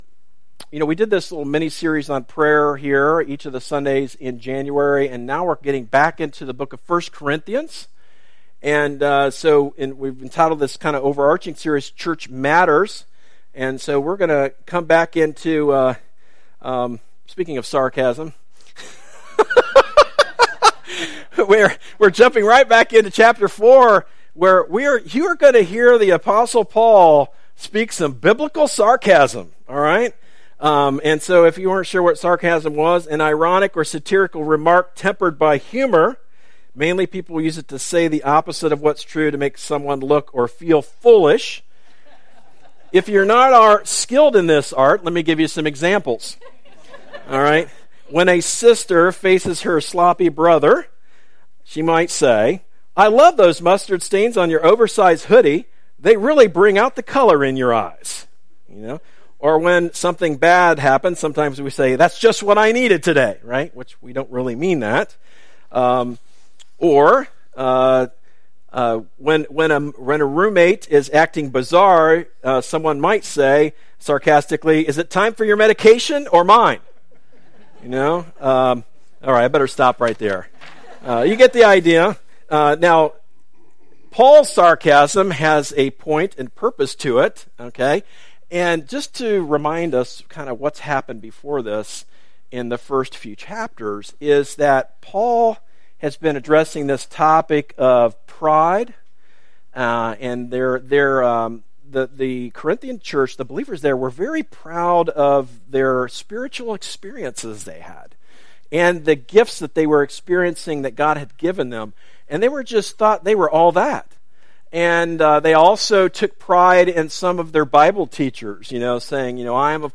A message from the series "Church Matters."